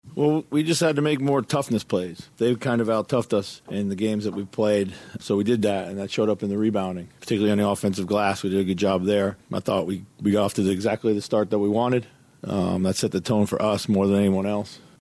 Minnesota head coach Chris Finch credits the front court’s toughness for the win.